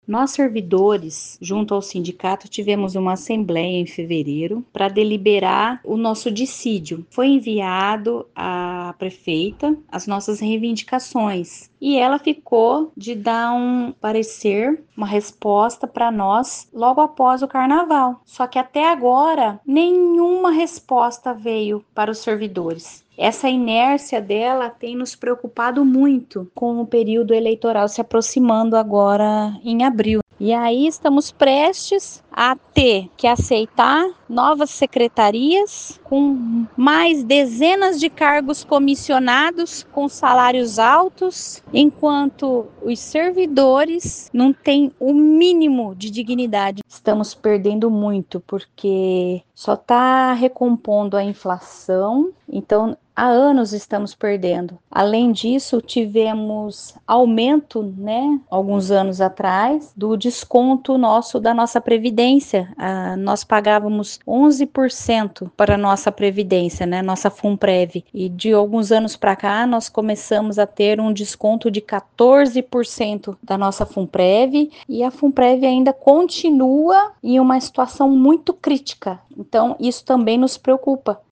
A preocupação dos servidores municipais é por conta do período eleitoral, a partir de abril, que proíbe eventuais compensações das perdas salariais, conforme explica uma das servidoras engajados no movimento, que a reportagem mantém a identidade sob sigilo.